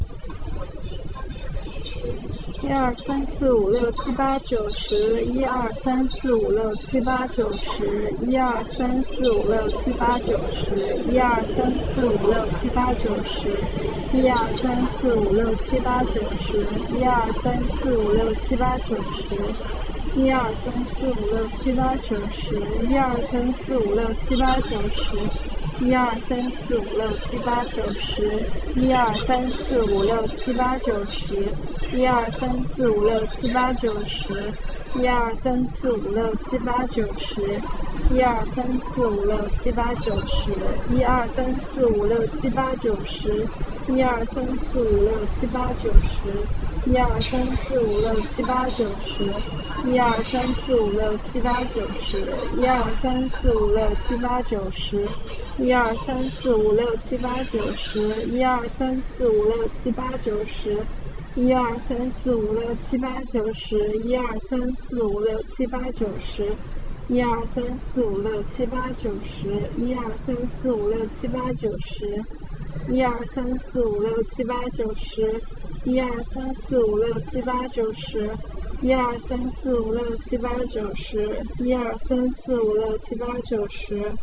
The recording was made with real equipment (two omnidirectional microphones with a distance of about 2 cm) in a real Chinese subway station.
We apply the directional enhancement function of our algorithm to enhance the voice of our female colleague.
metro_duetisr_enhanced.wav